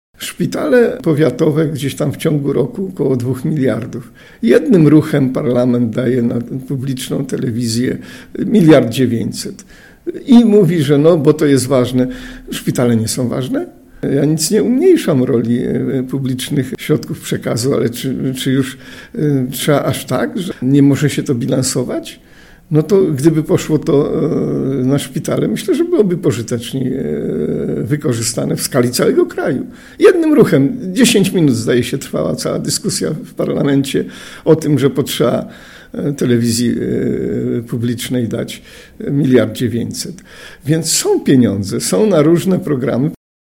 WYSŁUCHAJ całej rozmowy z Józefem Matysiakiem, starostą powiatu rawskiego TUTAJ>>>